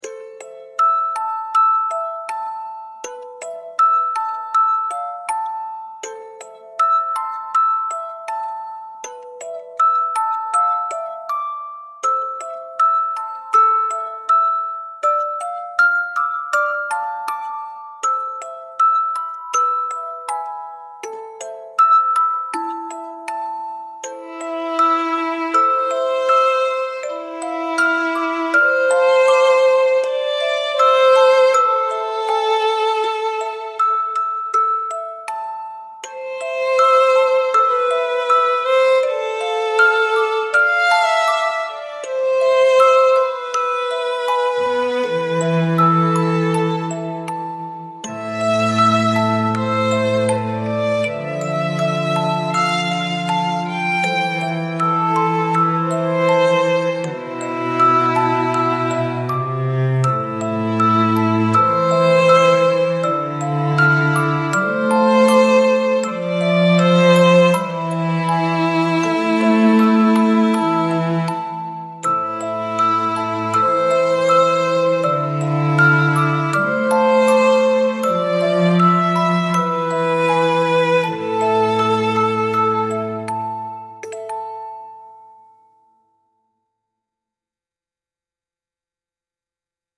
delicate music box and string quartet with a slow waltz rhythm